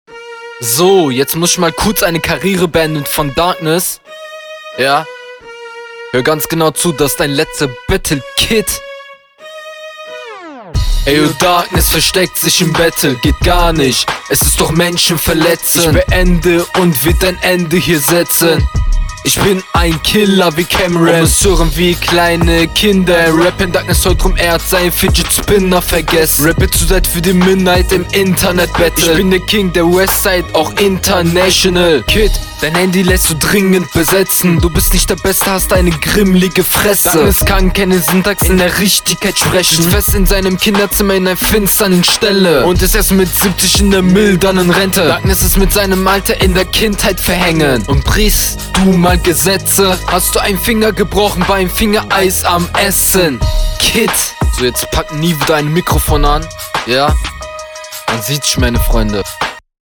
Die ersten vier Zeilen sind gut im Takt.